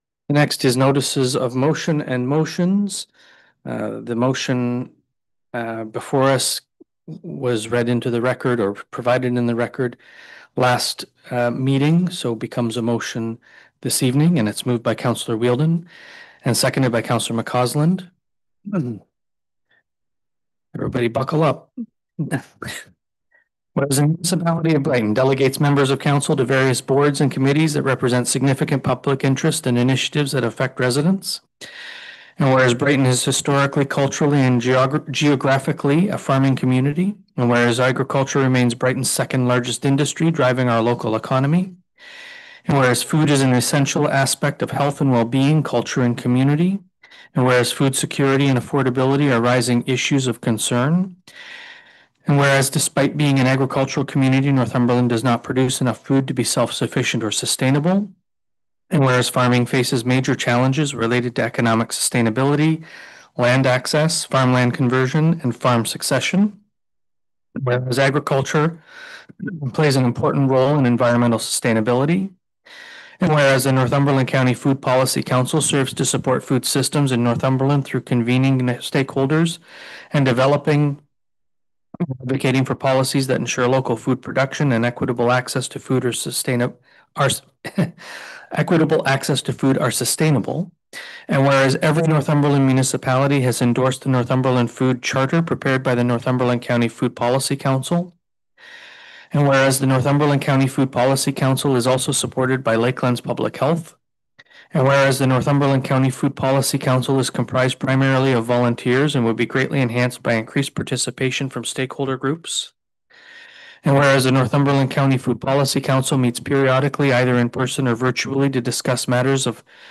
BRIGHTON-FOOD-POLICY-COUNCIL-DEBATE.FINAL_-1.mp3